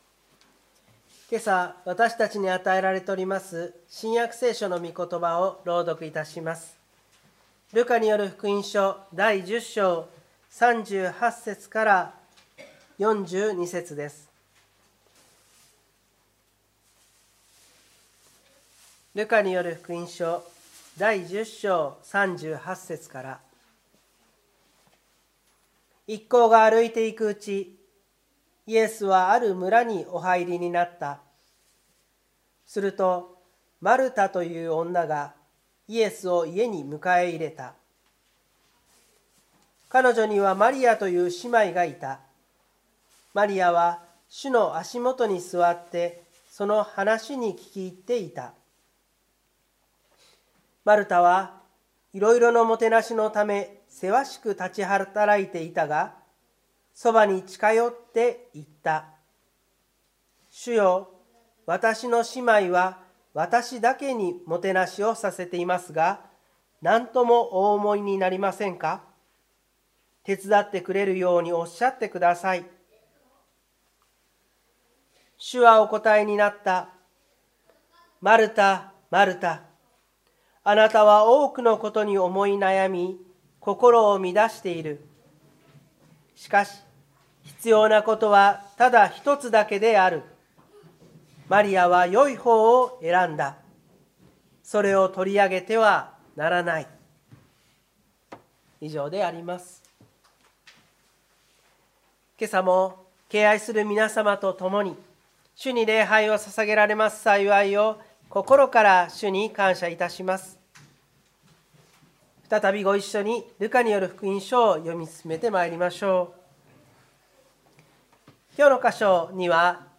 湖北台教会の礼拝説教アーカイブ。